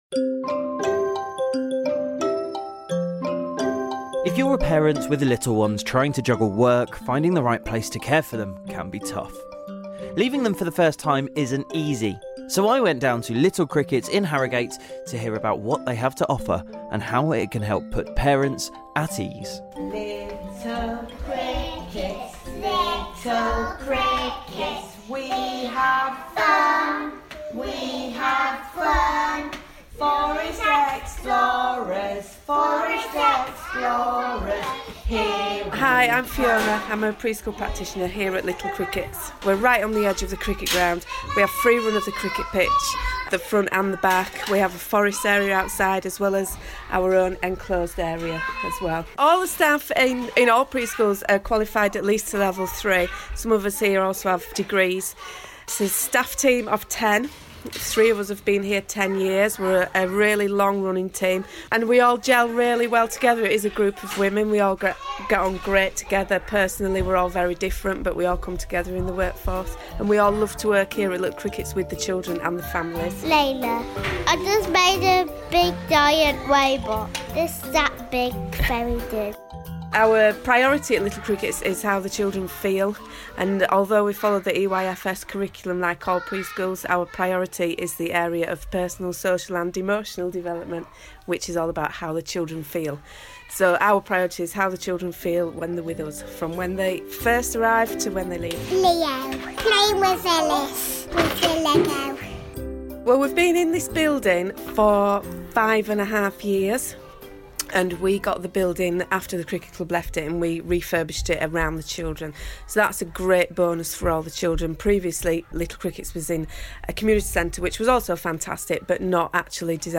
We went to Little Crickets in Harrogate to find out...